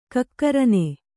♪ kakkarane